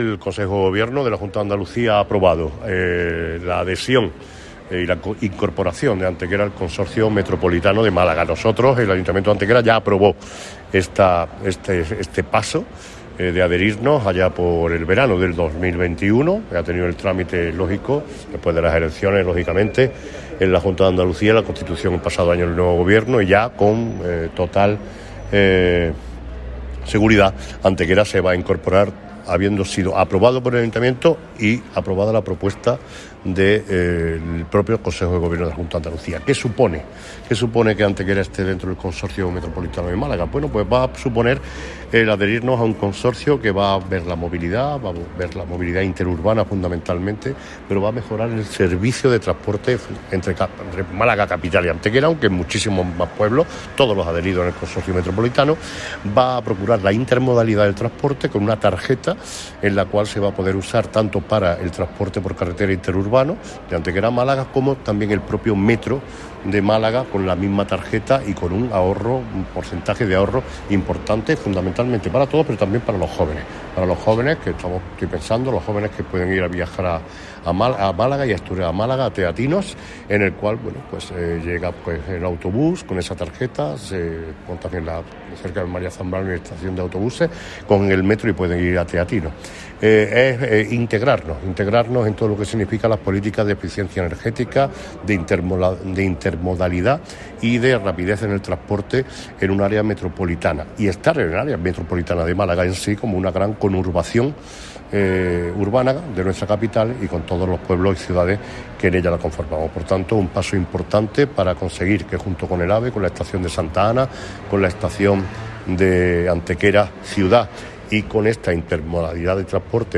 El alcalde de Antequera, Manolo Barón, valora positivamente el anuncio realizado en el día de ayer por parte de la Junta de Andalucía en cuanto a la aprobación por parte del Consejo de Gobierno el Plan de Transporte Metropolitano del Área de Málaga, hecho que permite la incorporación de nuevos municipios –caso de Antequera– al Consorcio de Málaga.
Cortes de voz